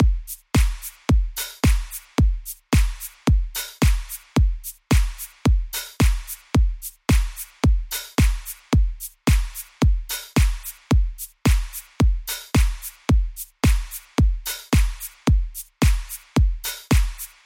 热带包4个大鼓
Tag: 110 bpm House Loops Drum Loops 2.94 MB wav Key : Unknown